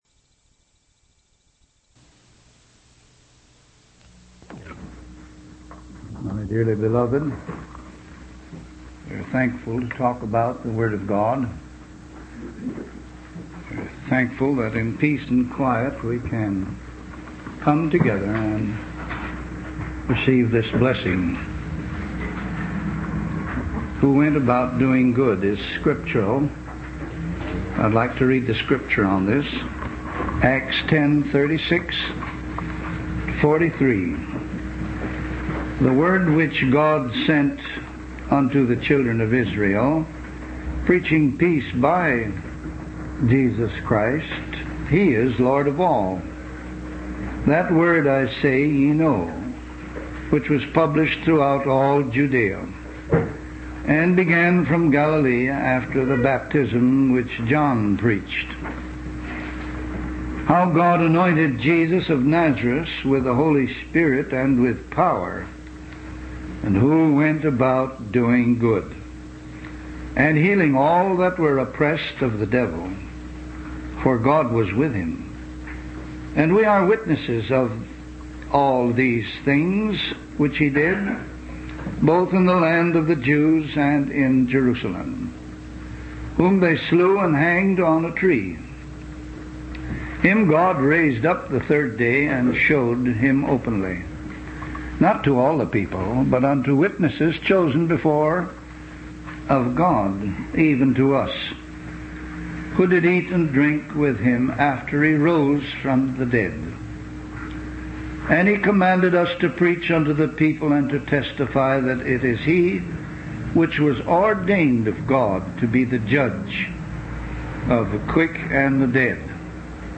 From Type: "Discourse"
Given in Vancouver, BC in 1958